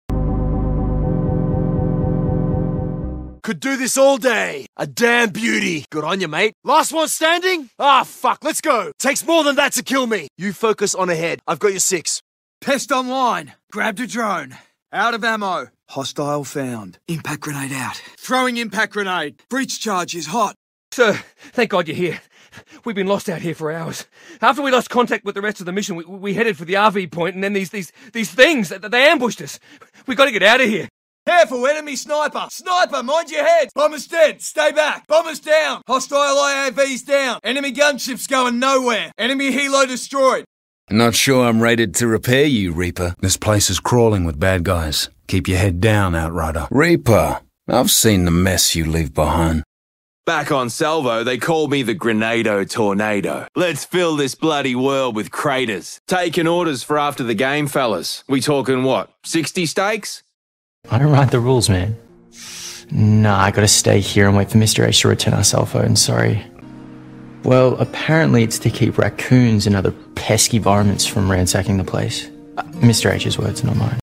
Australian voice claims, tried my sound effects free download
They're all actual Aussie VA's. Fem version?